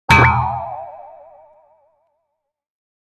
Cartoon Bonk Sound Effect
A comedy goofy hit with a boing sound adds a fun and playful effect to any animation. This cartoon bonk sound effect enhances TikTok and YouTube videos with a quick, clear, and humorous impact.
Cartoon-bonk-sound-effect.mp3